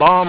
w1_bomb.wav